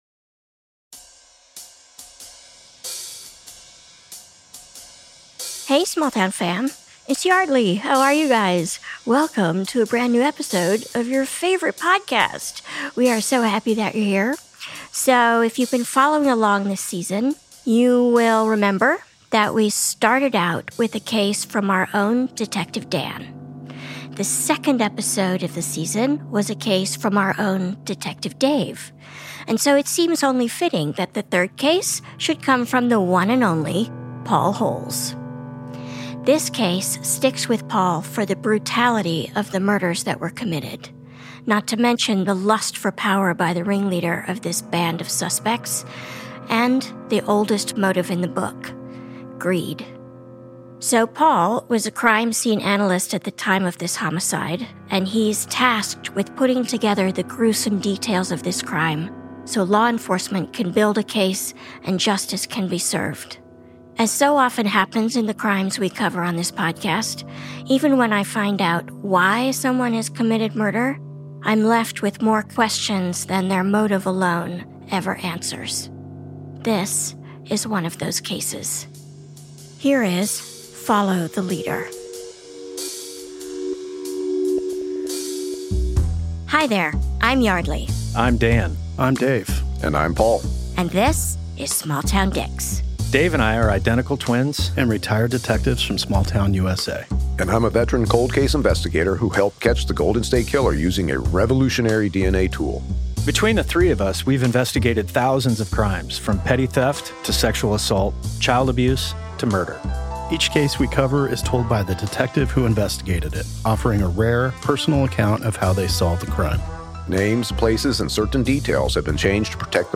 Forensic specialist Paul Holes recalls the case of a mass-murdering stockbroker bent on stealing his clients' money to start a new cult. The plan goes horribly wrong, as does the initial forensic investigation.
Guest: Paul Holes Paul Holes is a bestselling author, podcaster, television host and retired cold-case investigator with the sheriff’s and district attorney’s offices in California’s Contra Costa County.